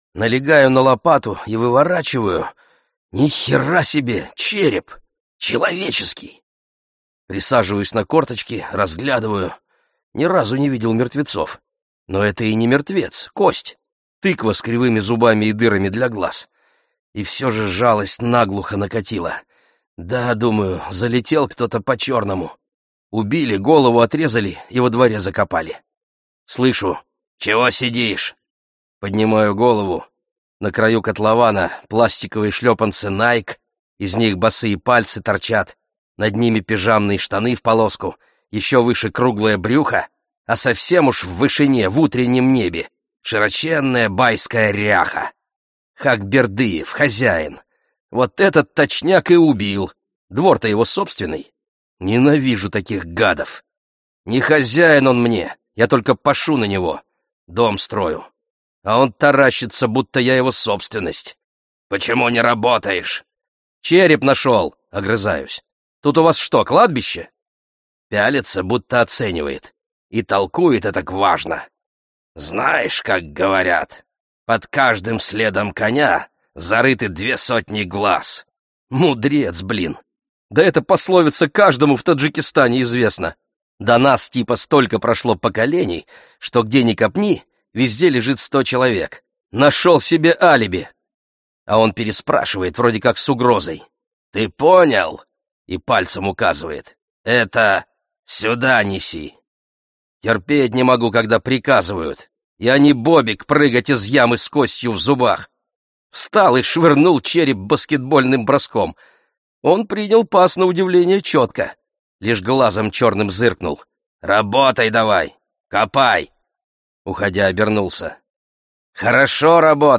Аудиокнига Заххок - купить, скачать и слушать онлайн | КнигоПоиск